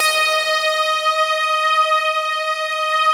SI1 PLUCK09R.wav